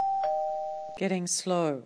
The Enhanced AlphaSystemsAOA calls out a clear early warning: ("Enhanced" means it includes an Aural Early Warning of
"Getting Slow" or the "Deedle-Deedle" tone) when we exceed a certain angle of attack.